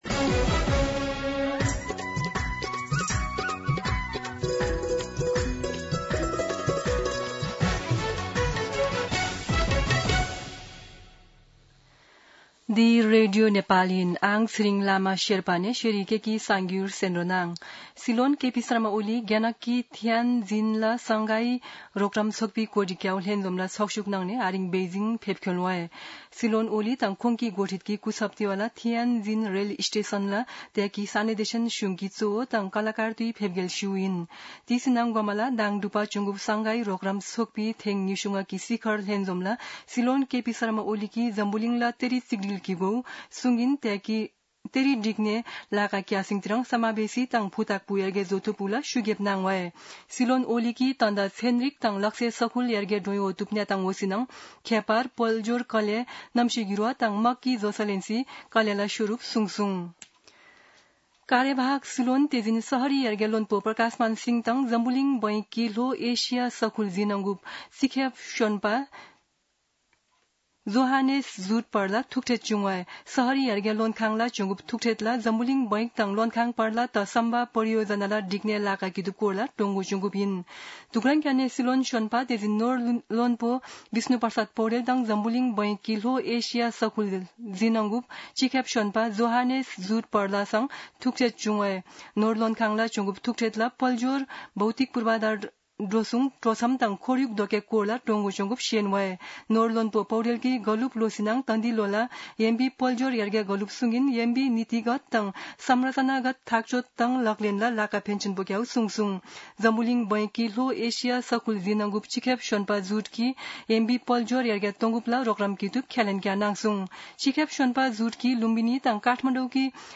शेर्पा भाषाको समाचार : १७ भदौ , २०८२
Sherpa-News.mp3